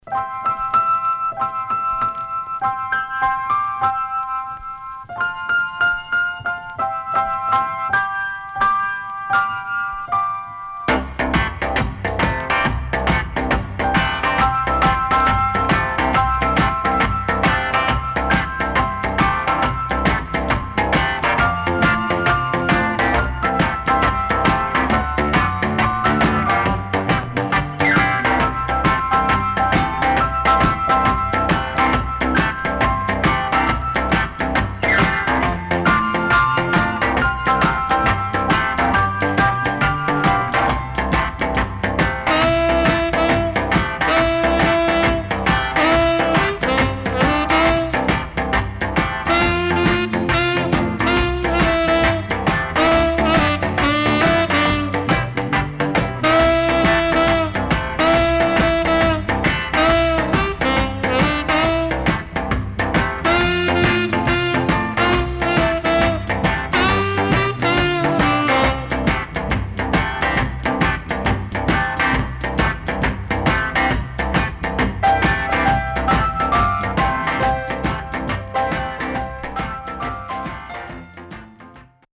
SIDE A:薄くヒスノイズ入りますが良好です。